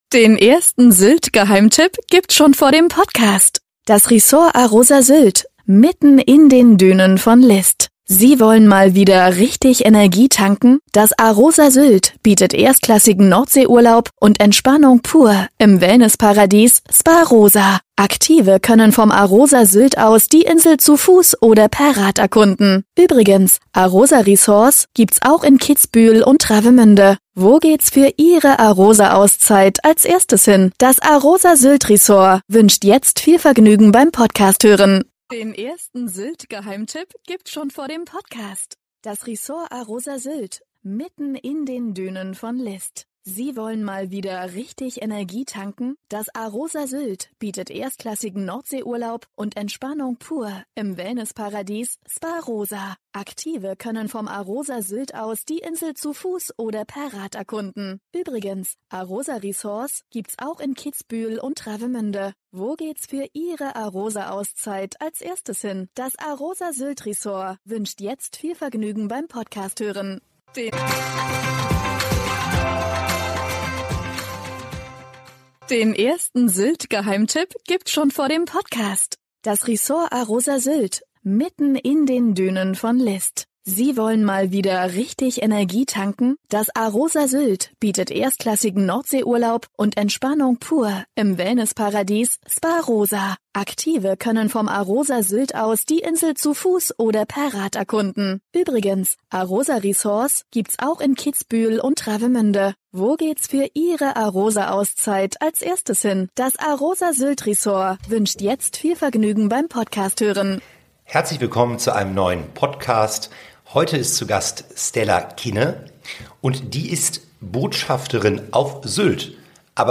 Sylt – der Insel-Talk